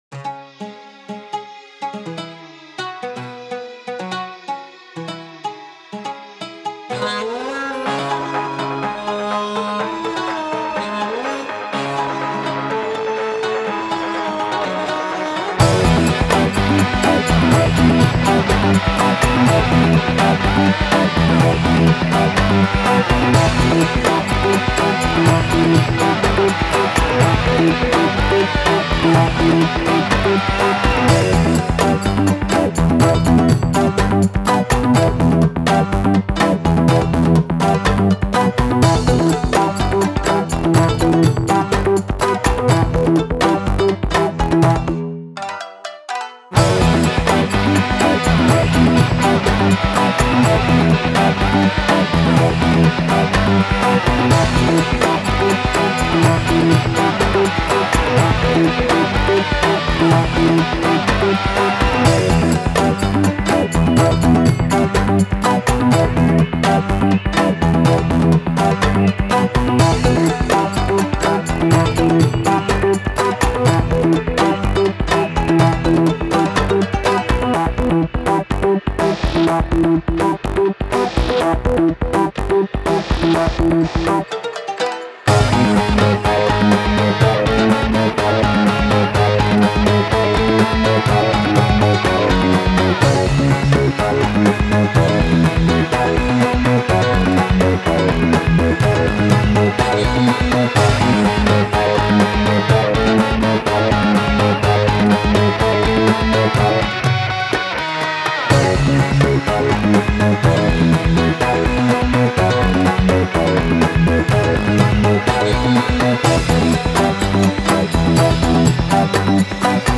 Genre: Ethnic Ambient
Instruments: guitars, programming